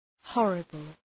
Προφορά
{‘hɔ:rəbəl}